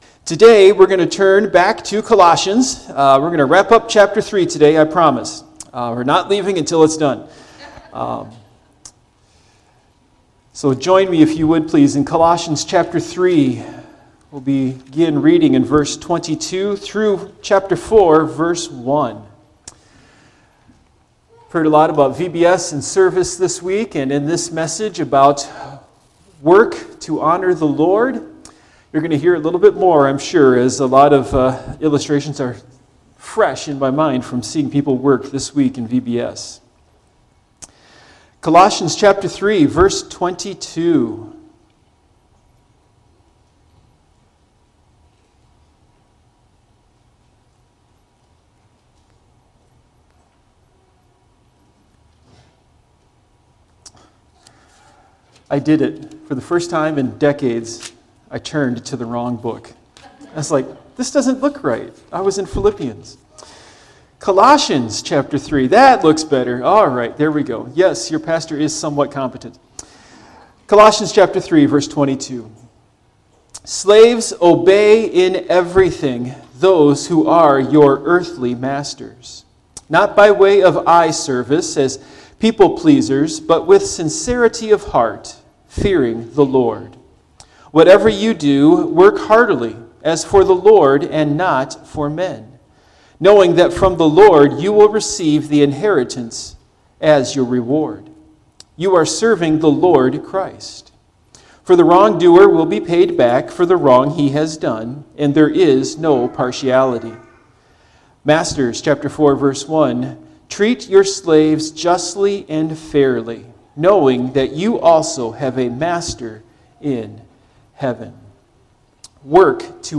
Passage: Colossians 3:22-4:1 Service Type: Morning Worship